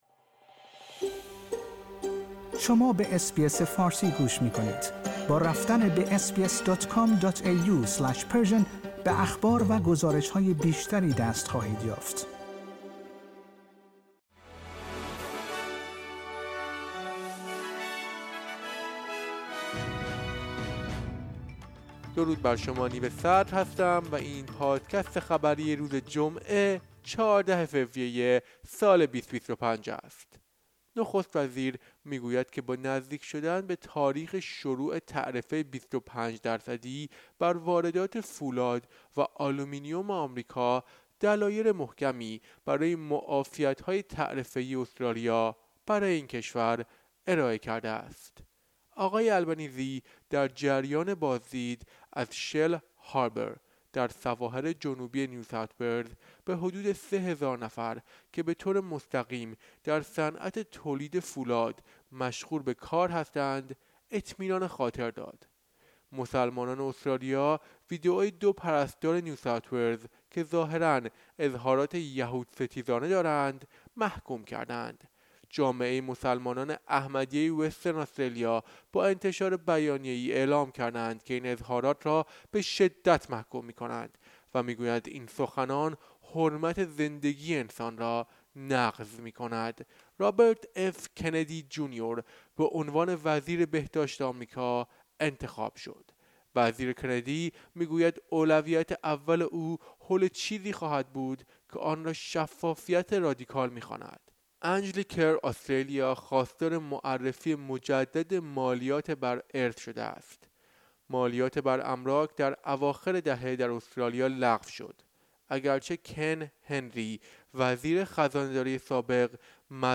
در این پادکست خبری مهمترین اخبار استرالیا در روز جمعه ۱۴ فوریه ۲۰۲۵ ارائه شده است.